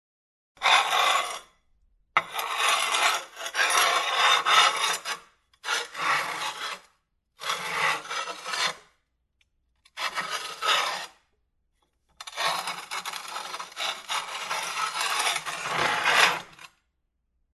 Звук скрежета металла о бетон или камень